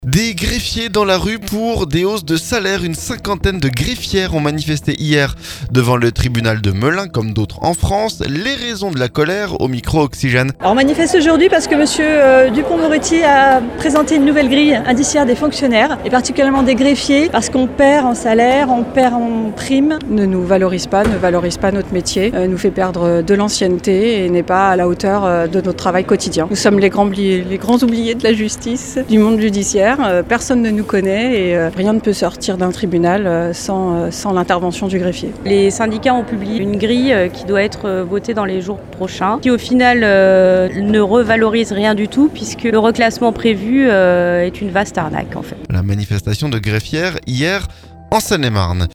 Une cinquantaine de greffières ont manifesté lundi devant le tribunal de Melun, comme d'autres en France. Les raisons de la colère au micro Oxygène.